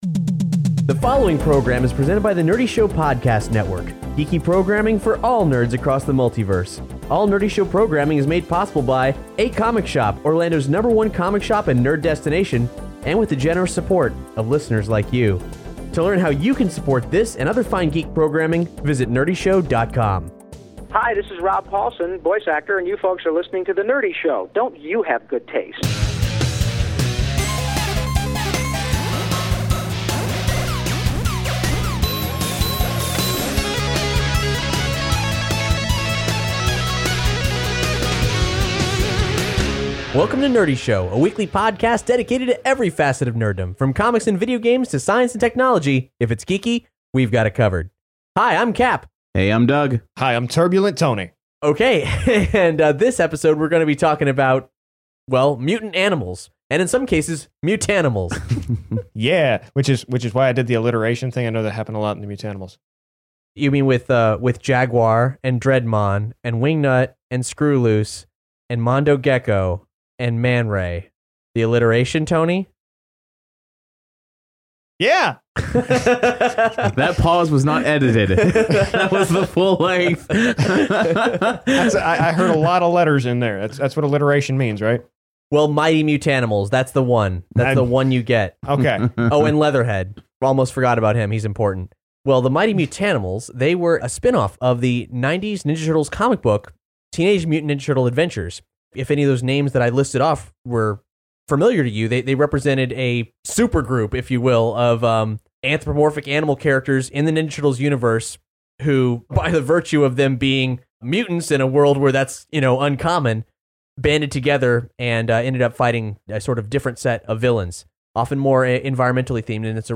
Hear TMNT co-creator Kevin Eastman discuss the legacy of the characters and the many forms the Turtles' universe has taken - from the Q&A we hosted at the '14 Heroes Con.